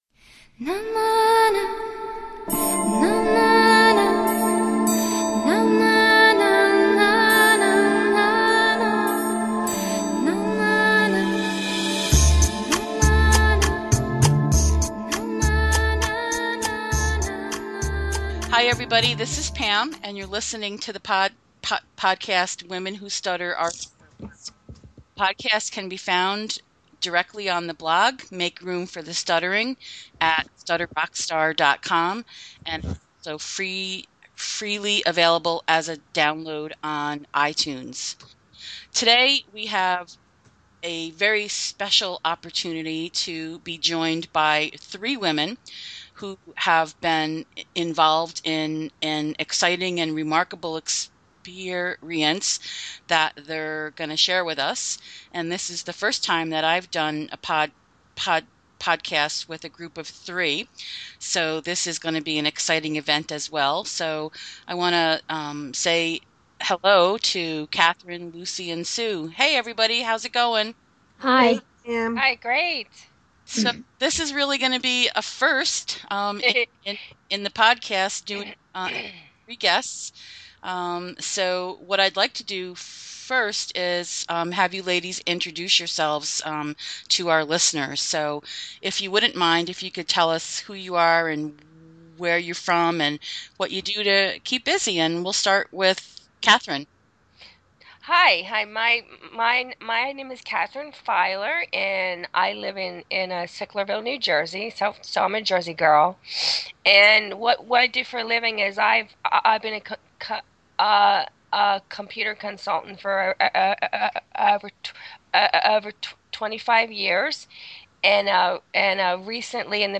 Episode 101 features three women who stutter who have all recently made their acting debuts.